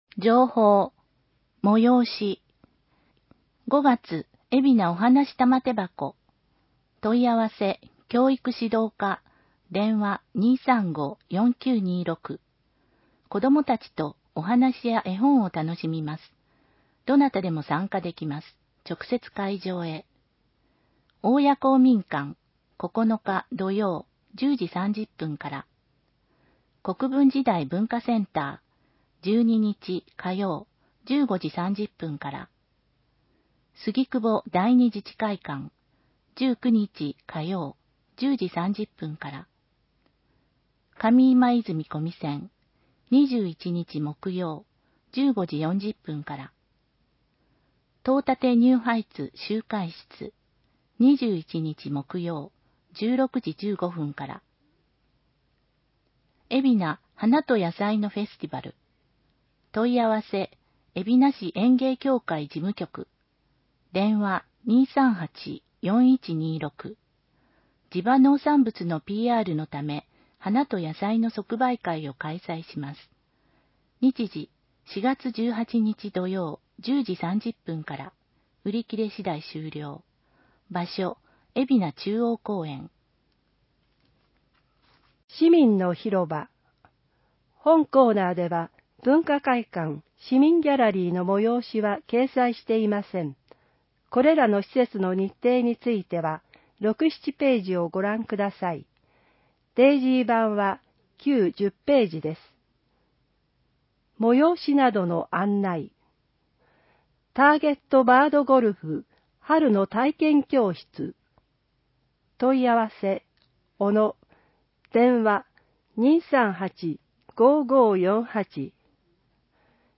広報えびな 平成27年4月15日号（電子ブック） （外部リンク） PDF・音声版 ※音声版は、音声訳ボランティア「矢ぐるまの会」の協力により、同会が視覚障がい者の方のために作成したものを登載しています。